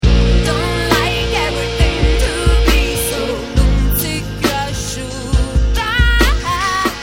voce e campioni
pianoforte, campionatore e programmazione
batteria, chitarre trattate e campioni
bassi elettronici e contrabasso